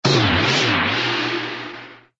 cogbldg_settle.ogg